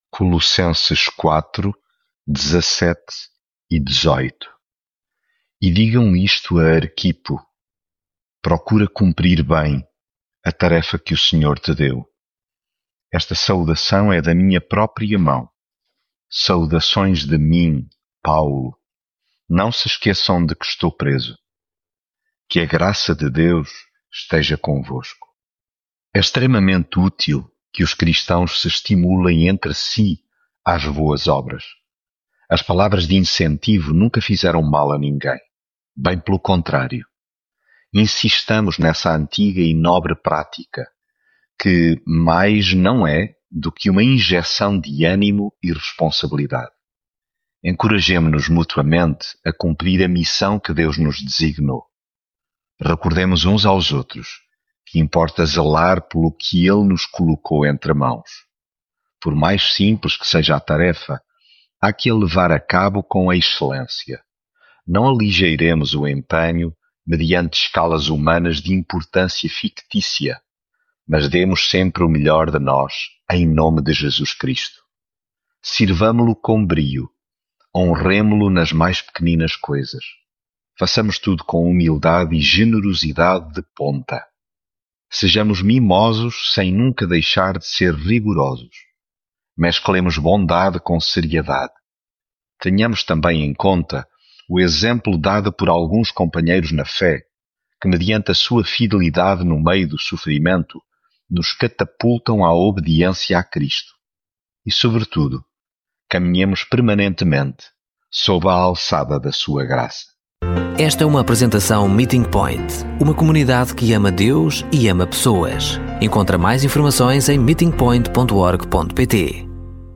Devocional
Leitura em Colossenses 4.17-18